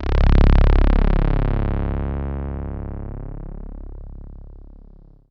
MOOG Taurus Pedal Synthesizer
moog_taurus.wav